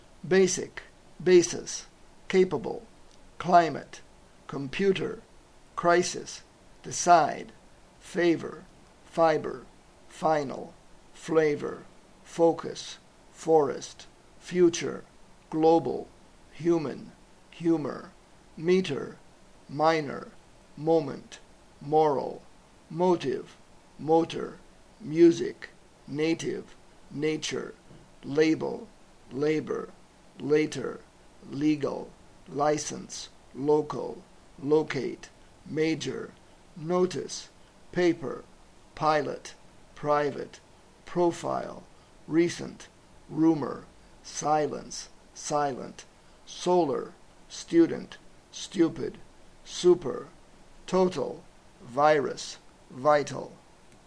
Casos en què es pronuncia el so llarg de la vocal